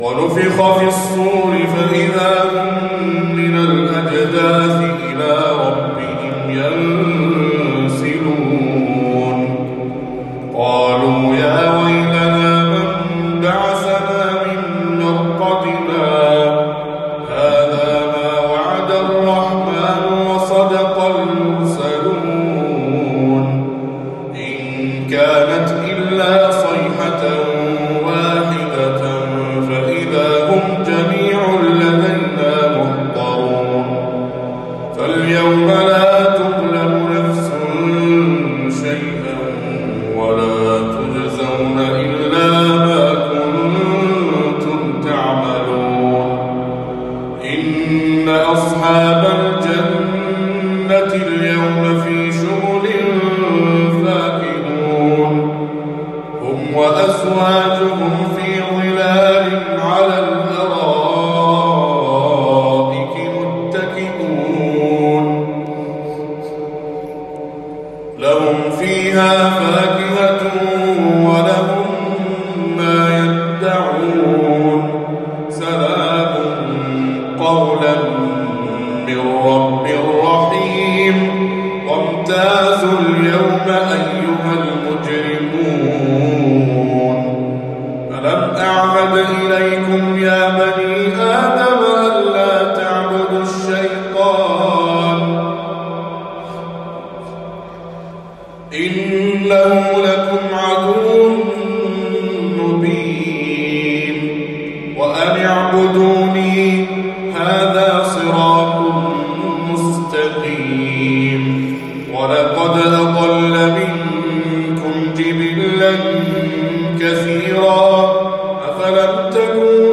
التجويد المتقن والصوت الخاشع تلاوة من سورة يس
جامع علي بن جبر آل ثاني ، البحرين